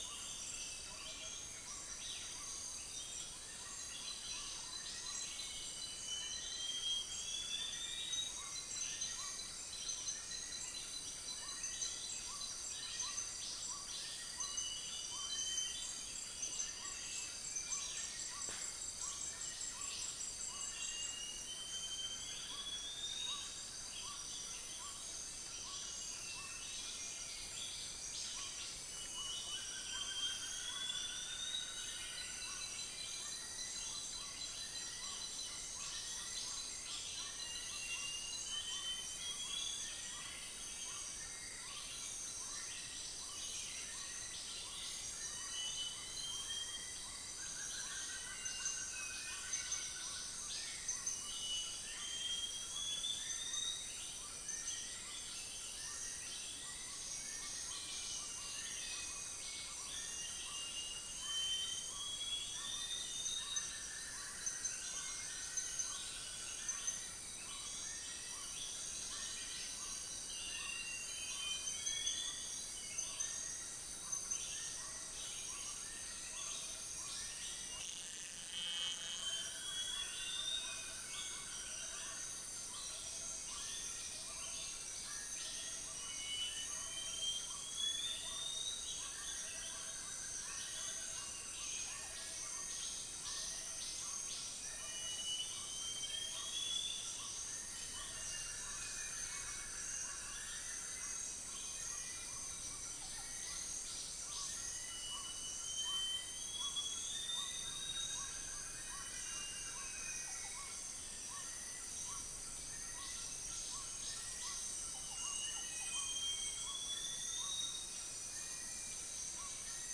Upland plots dry season 2013
Stachyris maculata
Harpactes duvaucelii
Malacopteron magnirostre
1 - mammal